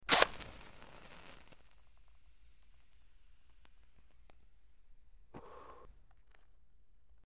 Sizzle; Match Strike, Ignite Flame And Blowout Close Perspective #6-7: Very Close Strike And Light, X7